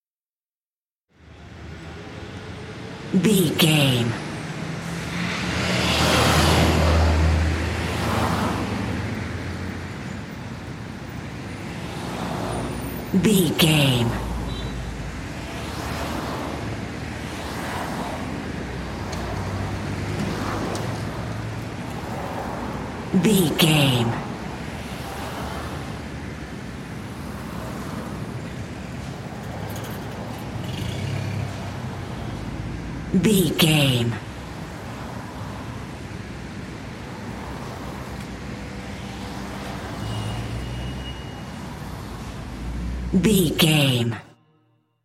Traffic cars passby
Sound Effects
urban
ambience